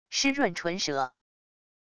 湿润唇舌wav音频